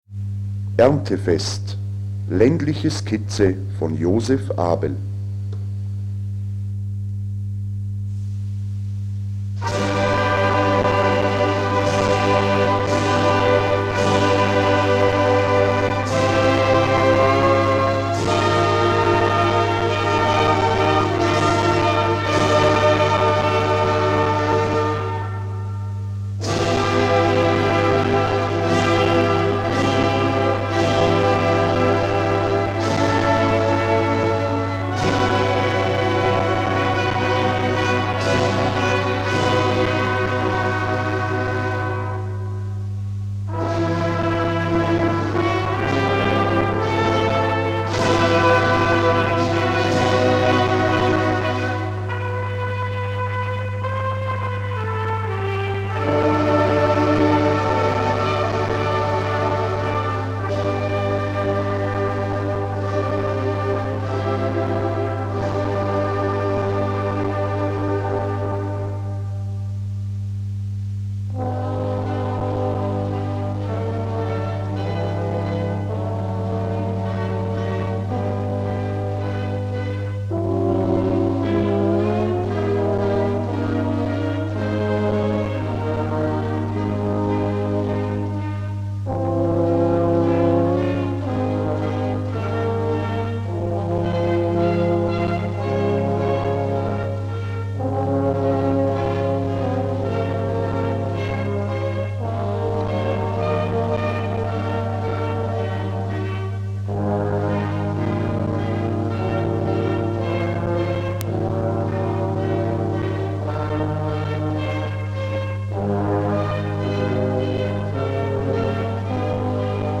Gattung: Ländliche Skizze
Besetzung: Blasorchester
Eine gehörfällige und abwechslungsreiche Komposition.